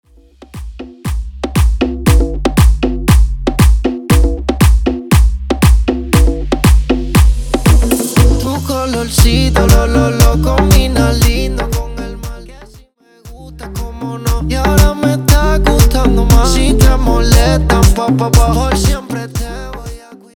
Coro Dirty